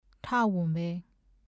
ဌဝမ်းဘဲ [tʰâ-wúɴbɛ́ ]子音字「ဌ」の名前。